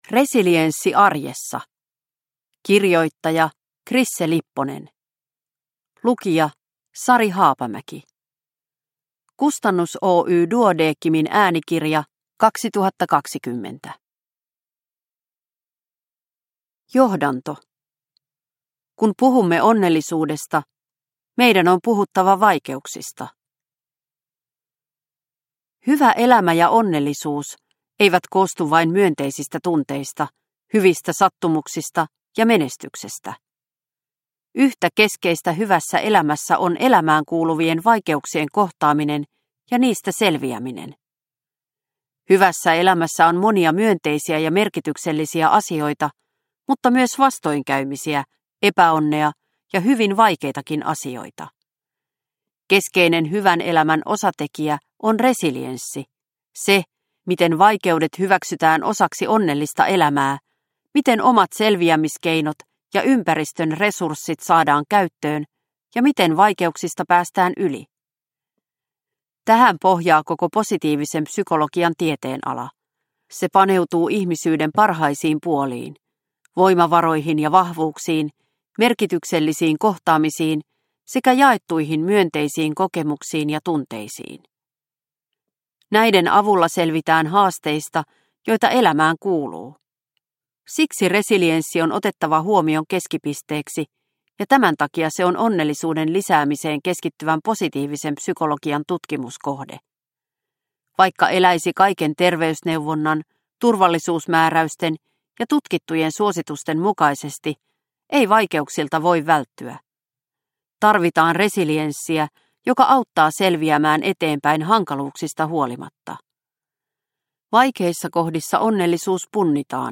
Resilienssi arjessa – Ljudbok – Laddas ner